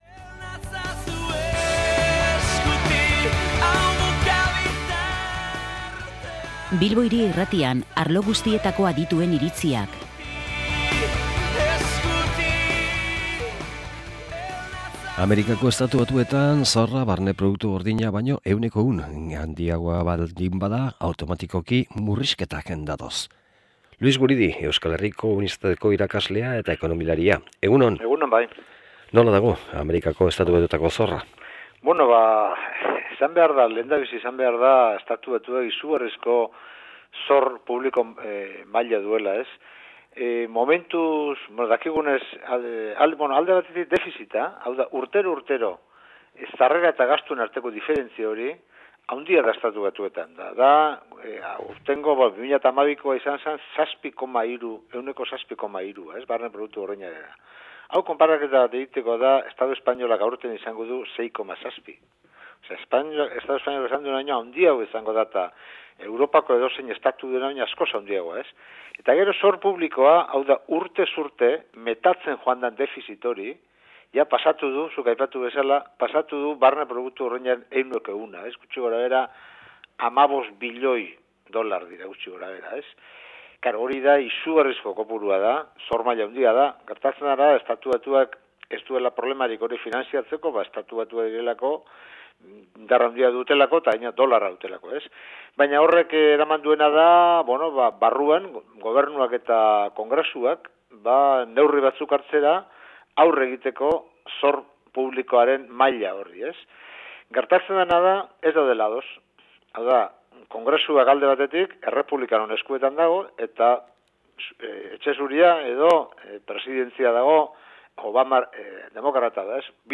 solasaldia